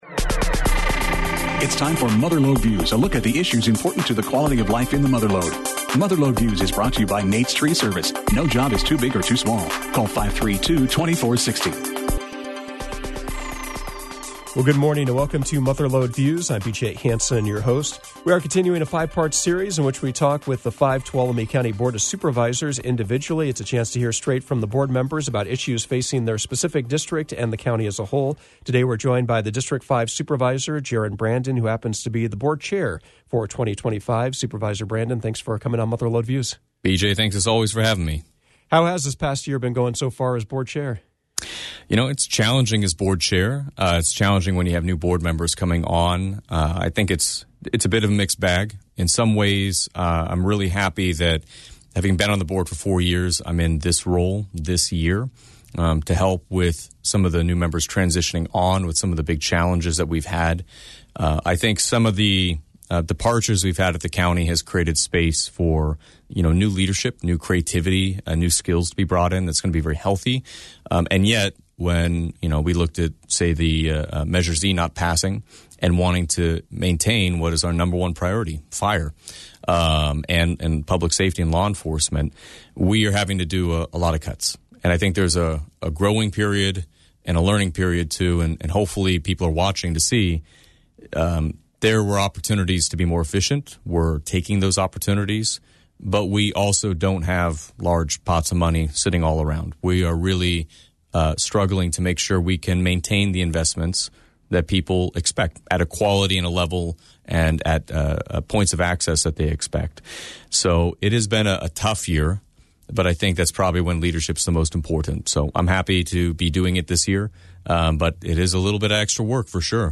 Mother Lode Views featured District Five Tuolumne County Supervisor Jaron Brandon, who represents Columbia and Jamestown. He talked about projects and challenges specific to those communities and also issues facing the whole county.